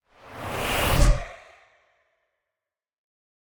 bad_omen.ogg